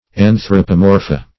Meaning of anthropomorpha. anthropomorpha synonyms, pronunciation, spelling and more from Free Dictionary.
anthropomorpha.mp3